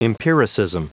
Prononciation du mot empiricism en anglais (fichier audio)